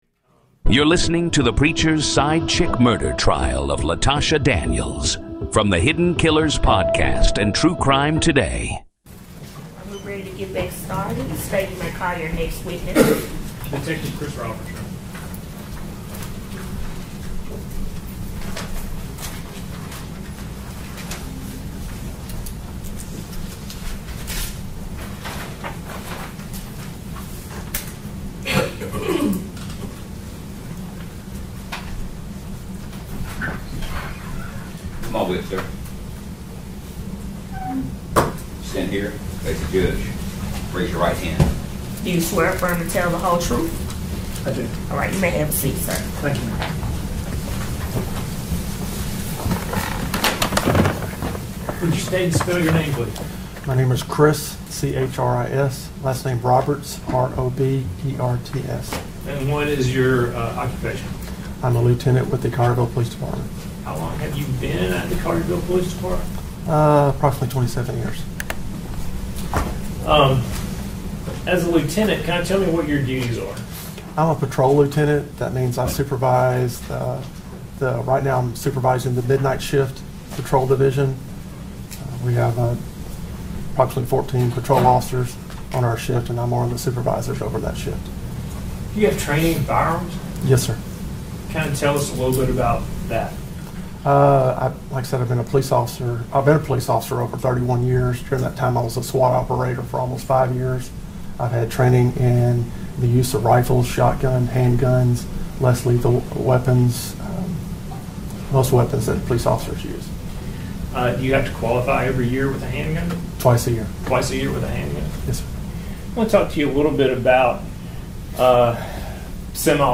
Raw, unedited courtroom coverage